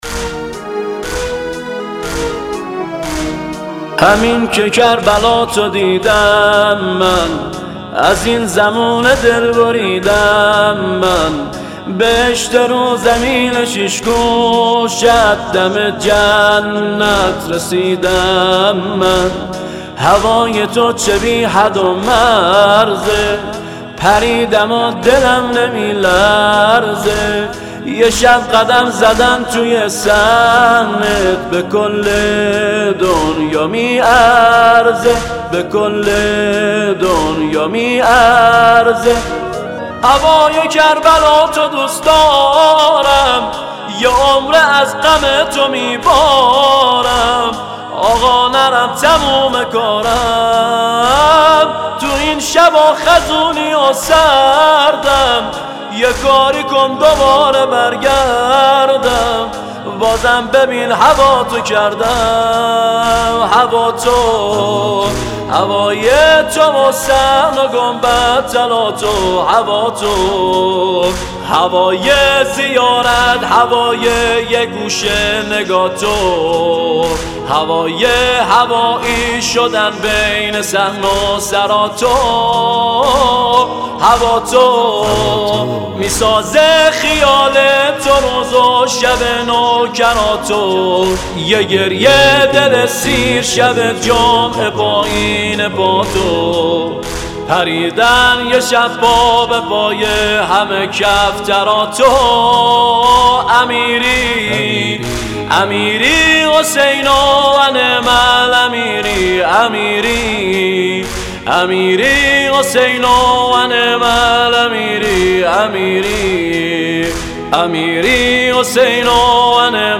نوحه تک همین که کربلاتو دیدم من